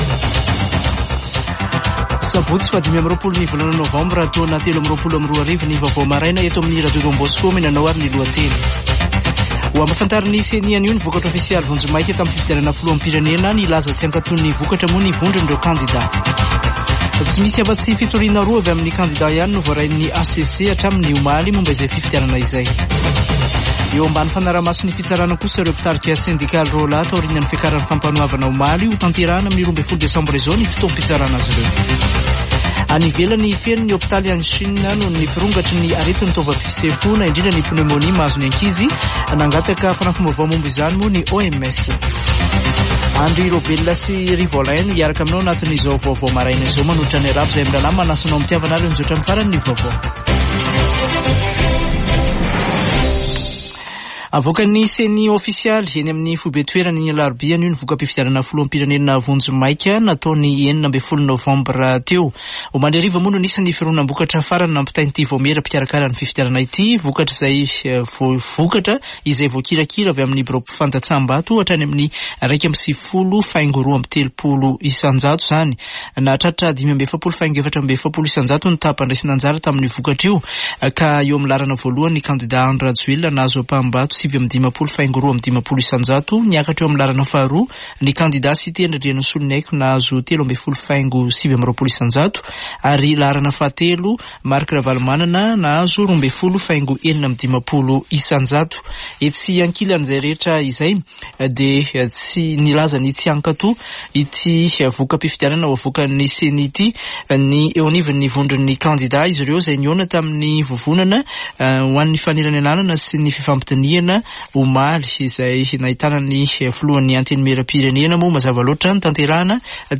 [Vaovao maraina] Sabotsy 25 nôvambra 2023